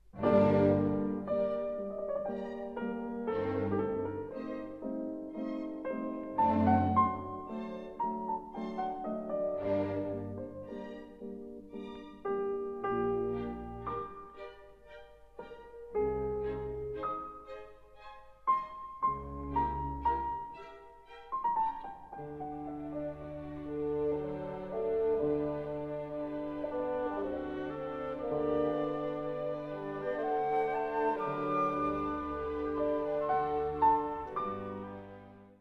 Stereo recording made on 20-23 July 1960
in No. 1 Studio, Abbey Road, London